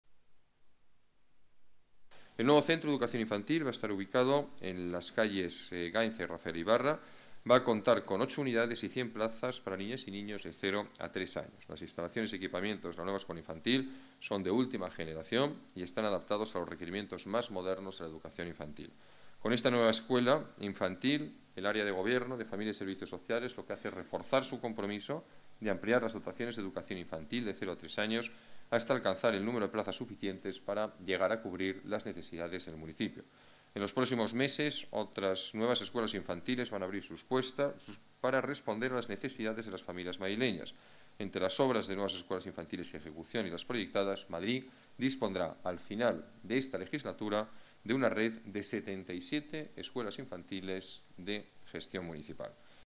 Nueva ventana:Declaraciones del alcalde de Madrid, Alberto Ruiz-Gallardón: Escuela Infantil Usera